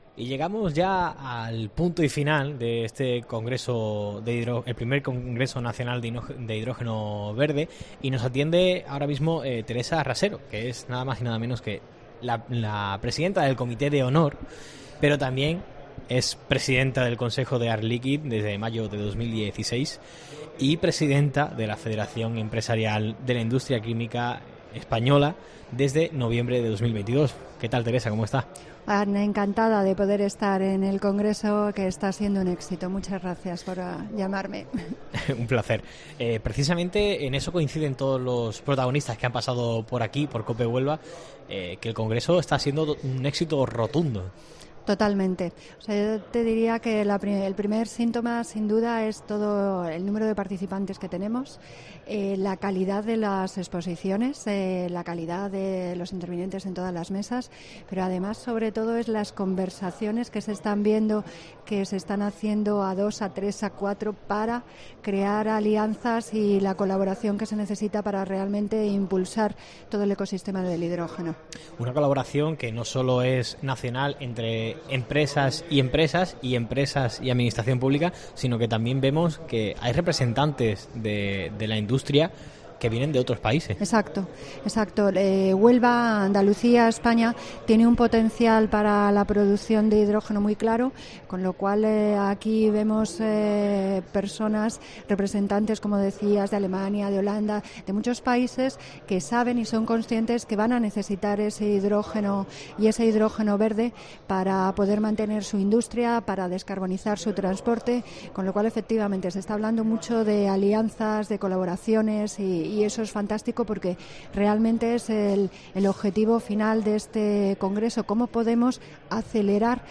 Durante la entrevista en COPE Huelva son varias las intervenciones